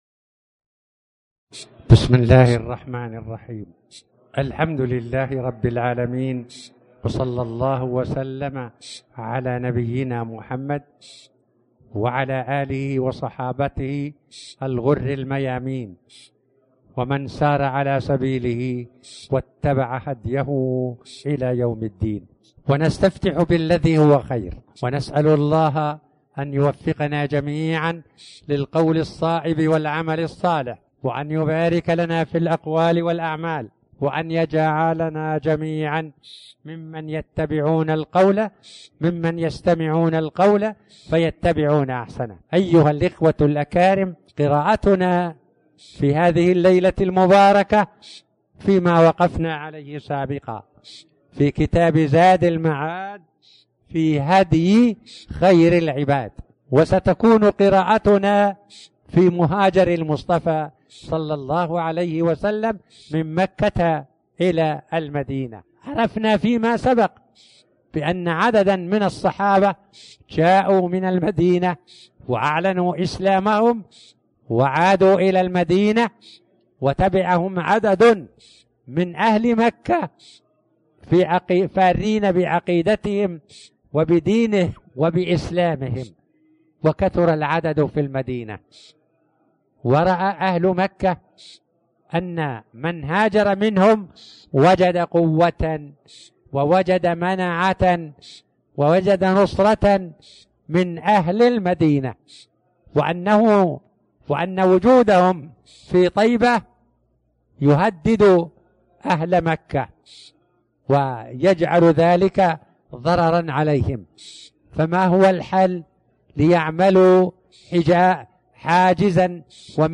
تاريخ النشر ١٢ شوال ١٤٣٩ هـ المكان: المسجد الحرام الشيخ